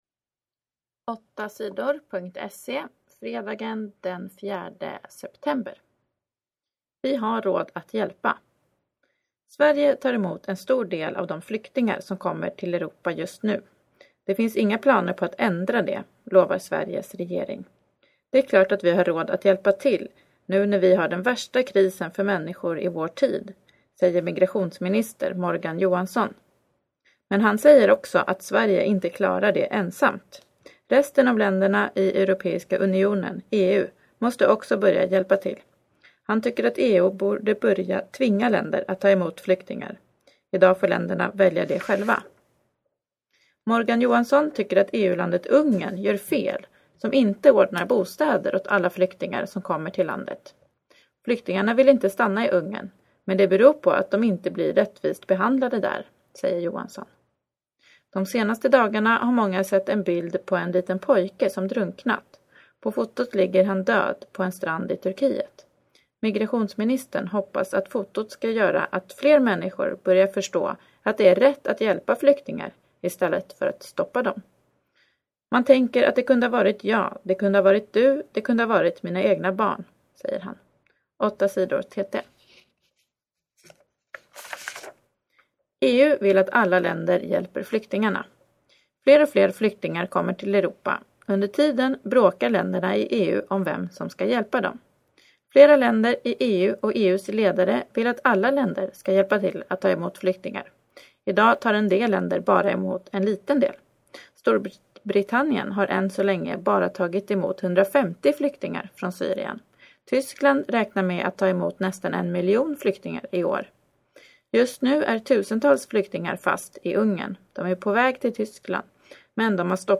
Lyssna på nyheter 4 september